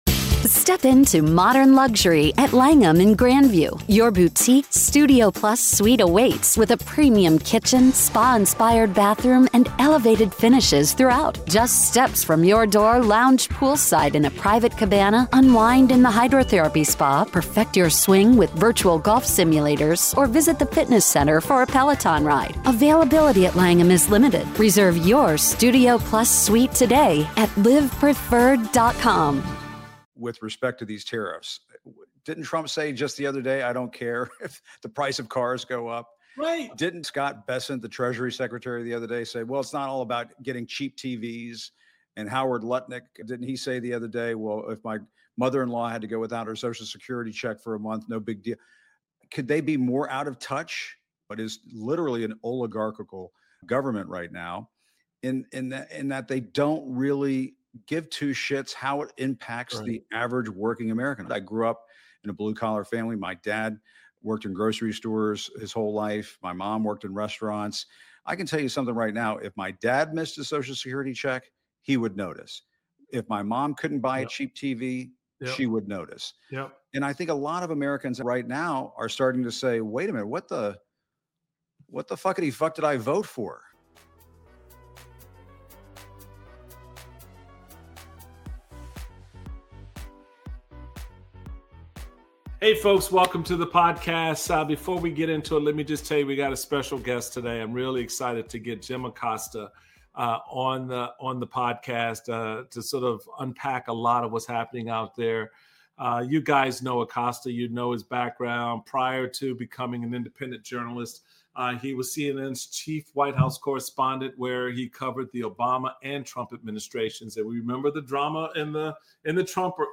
Michael Steele speaks with Jim Acosta about how Trump's out of touch tariffs will impact Americans and how journalists should approach reporting the second Trump term.